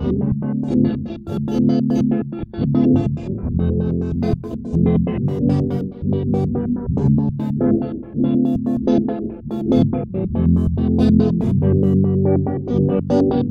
Guitar 05.wav